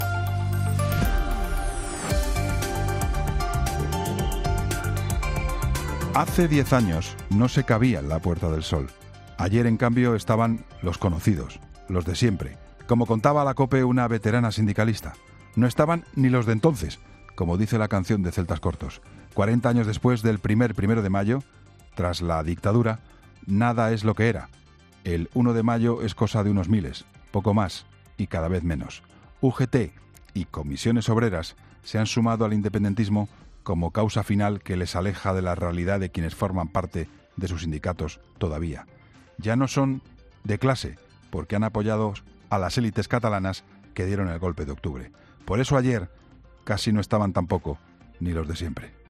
Escucha el comentario de actualidad del director de 'La Linterna', Juan Pablo Colmenarejo, en 'Herrera en COPE'